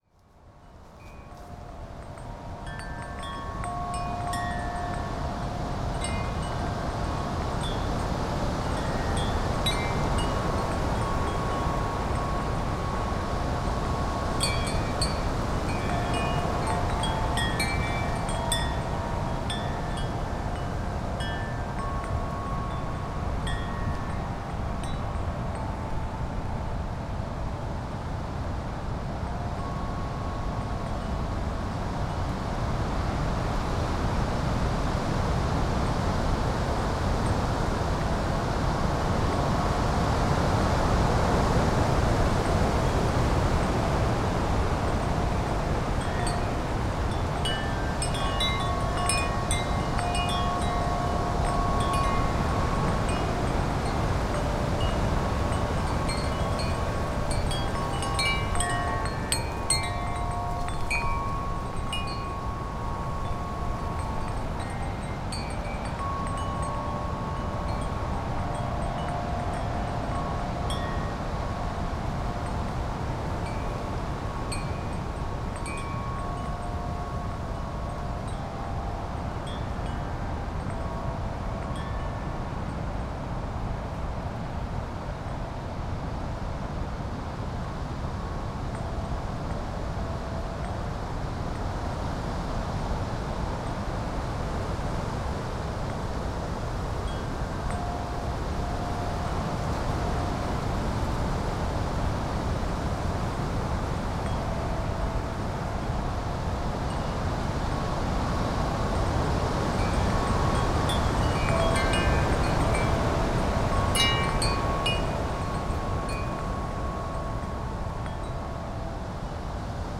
Wind Chimes in GALE over wooded valley - Gypsy Mezzo - excerpt
chimes Devon Drewsteignton England field-recording gale gusts Gypsy sound effect free sound royalty free Nature